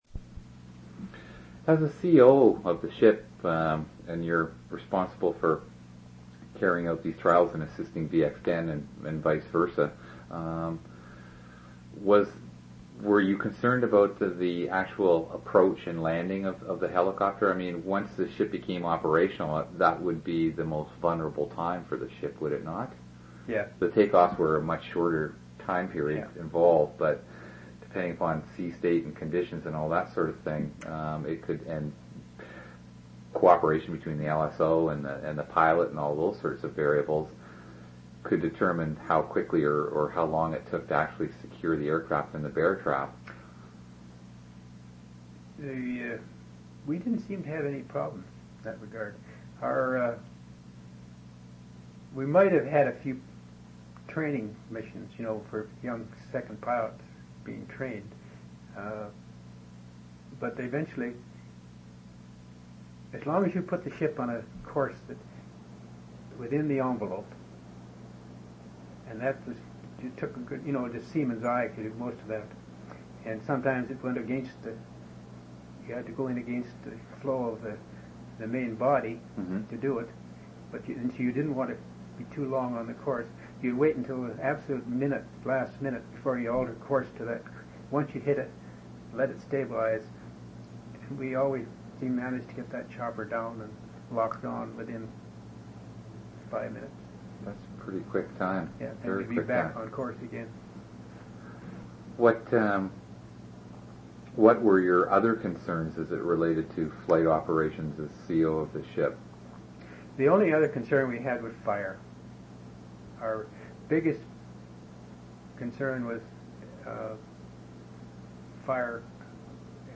interviews
oral histories